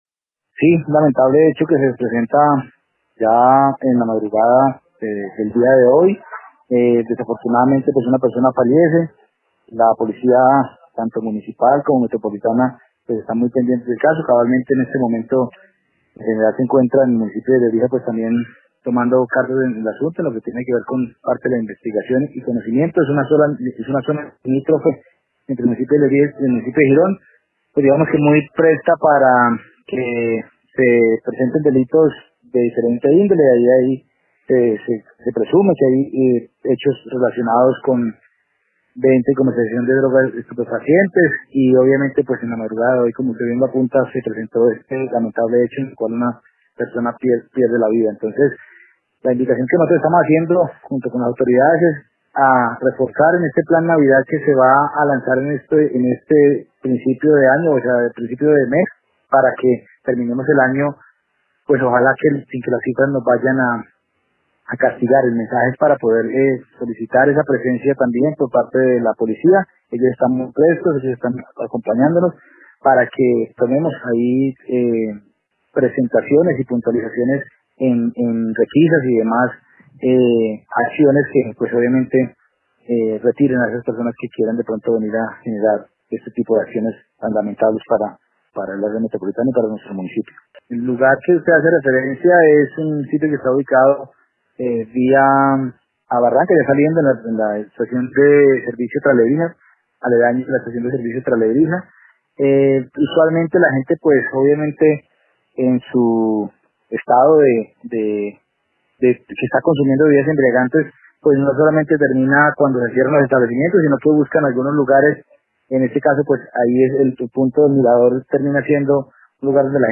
Alcalde de Lebrija, Gabriel Martínez Calderón
La discoteca Hawaii queda vía a Barrancabermeja, saliendo del municipio de Lebrija, “usualmente la gente en su estado de embriaguez buscan algunos lugares en este caso el punto del mirador, que es muy bonito y es el lugar donde se parquean y continúan ingiriendo bebidas embriagantes. Eso hace que se presenten este tipo de situaciones”, dijo en Caracol Radio Martínez Calderón.